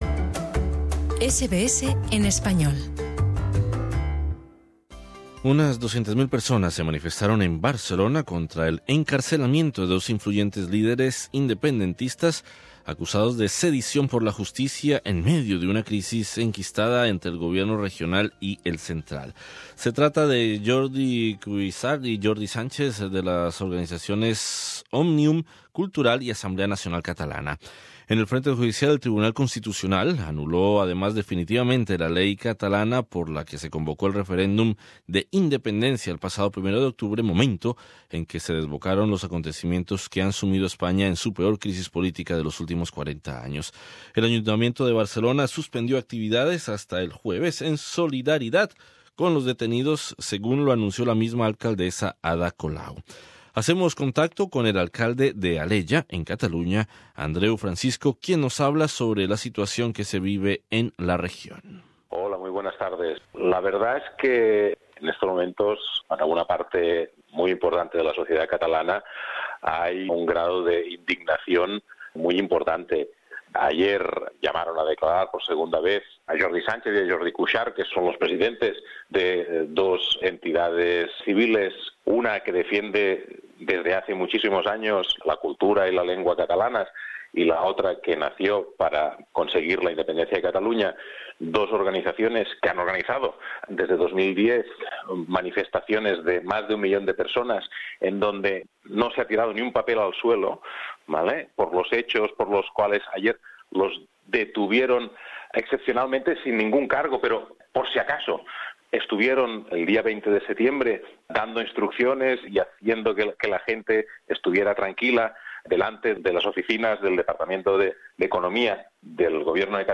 Sobre la situación en Cataluña entrevistamos al alcalde de Alella, Andreu Francisco.